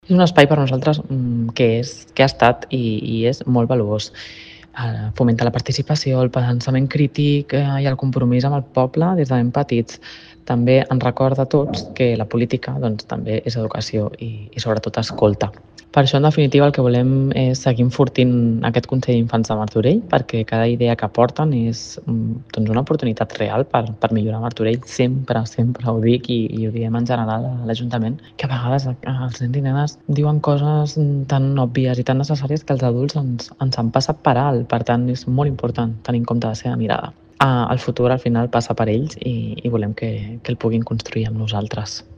Belén Leiva, regidora d'Infància i Adolescència